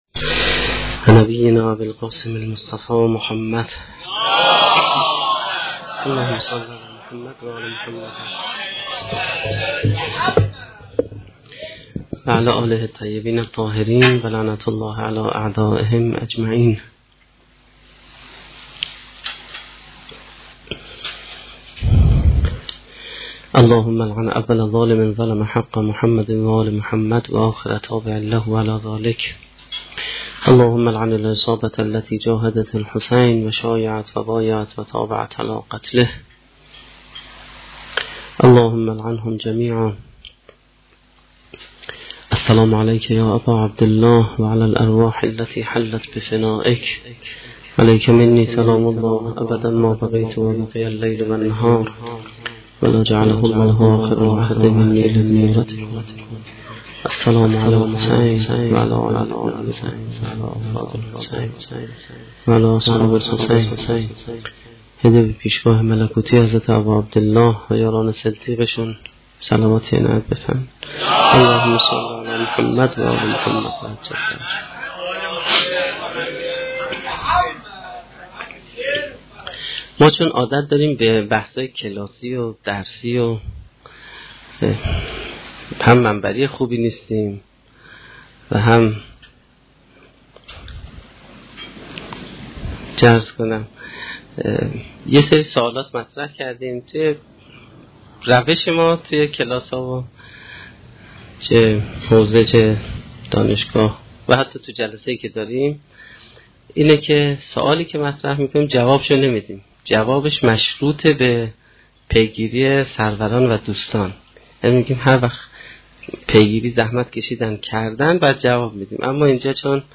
سخنرانی هفتمین شب دهه محرم1435-1392